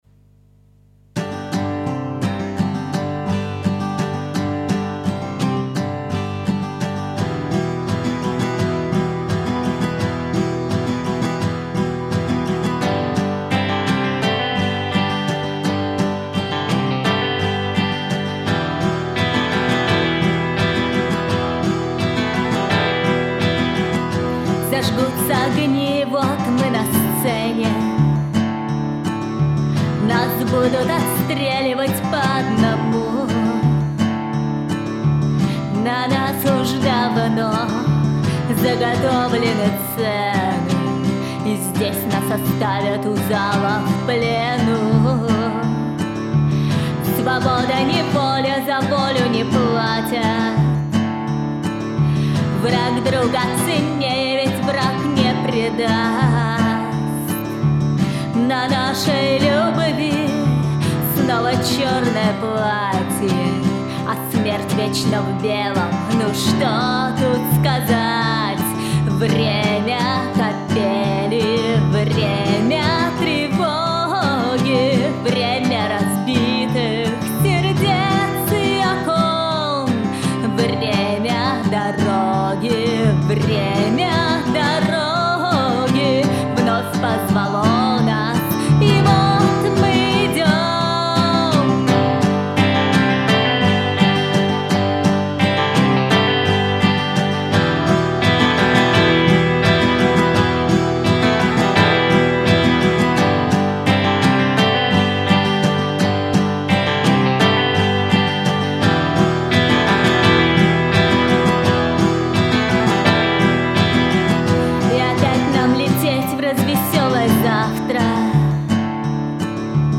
Песни у вас совершенно разные по стилистике.